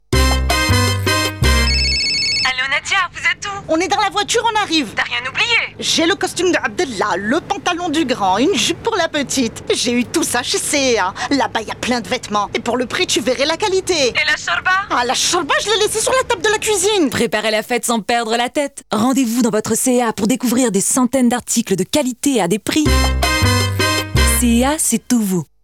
Publicité C&A en français